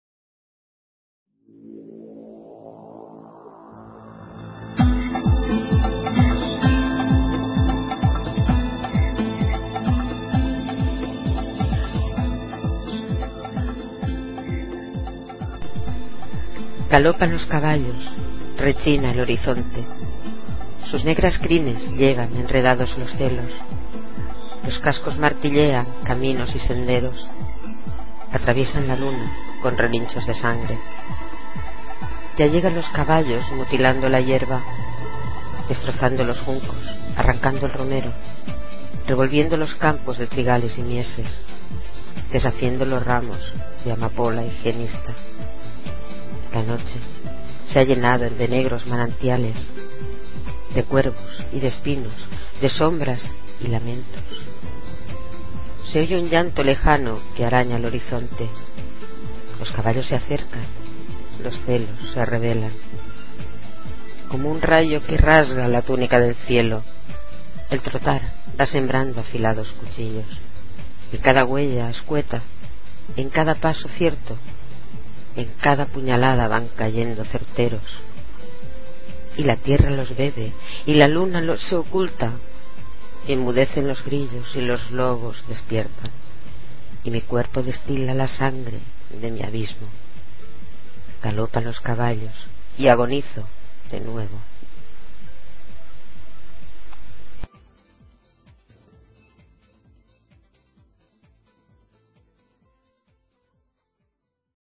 Inicio Multimedia Audiopoemas Galopan los caballos.
(Recitado